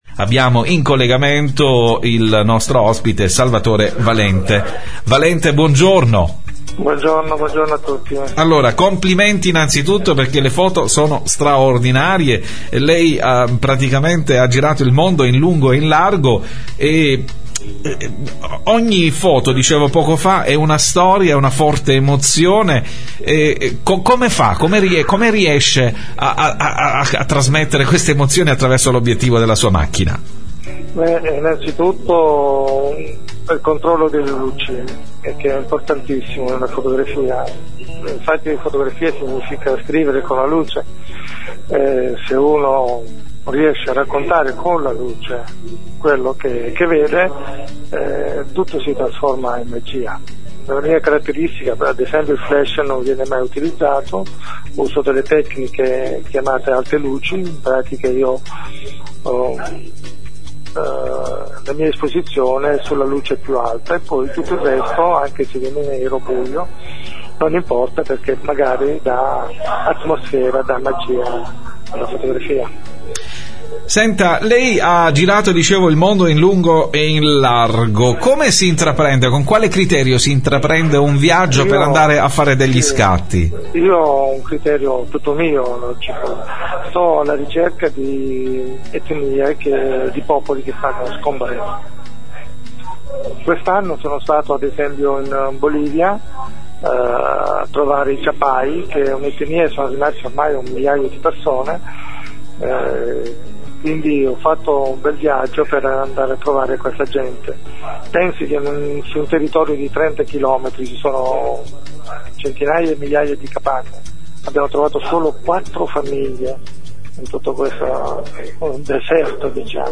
Intervista in occasione del seminario Fotografia di Reportage